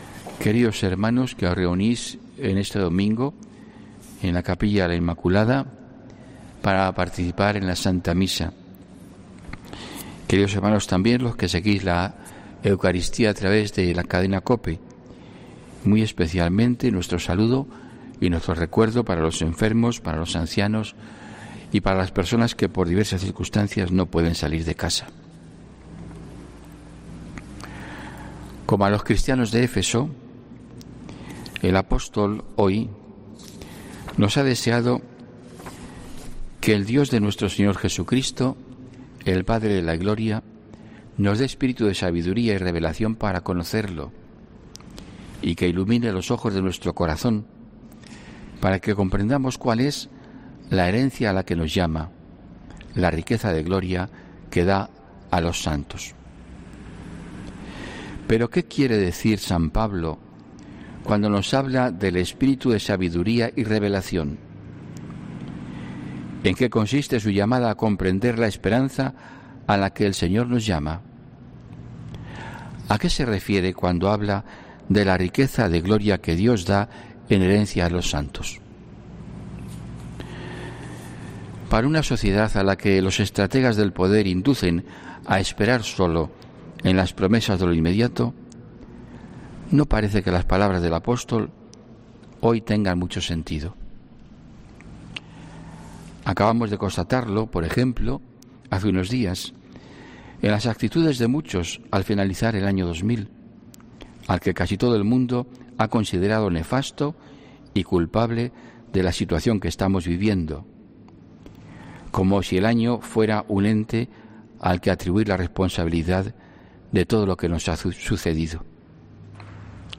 HOMILÍA 3 DE ENERO 2021